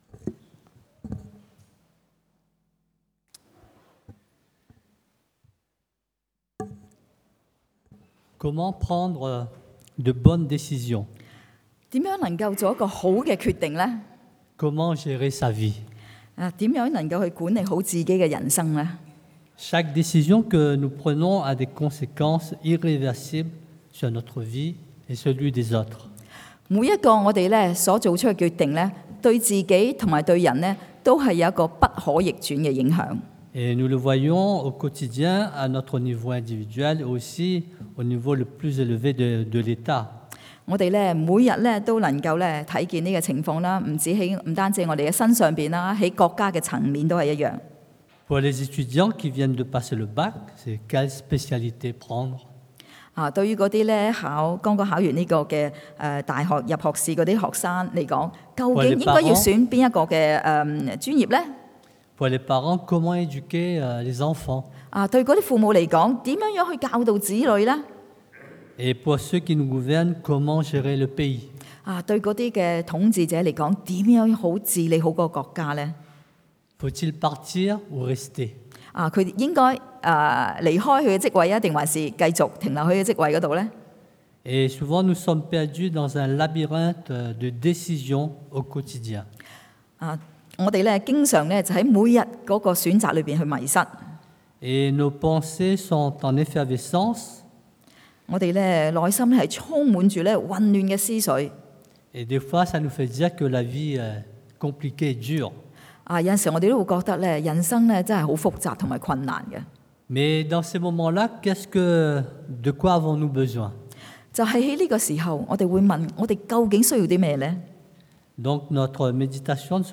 La sagesse d’en haut 从上头来的智慧 – Culte du dimanche
1 Rois 列王纪上 3:3-15 Type De Service: Predication du dimanche « L’ivraie et le bon grain 杂草与麦子 Homme de peu de foi